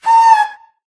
ghast / affectionate_scream
charge.ogg